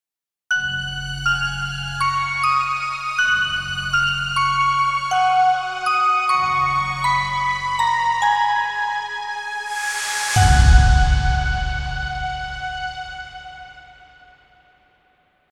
Download Happy sound effect for free.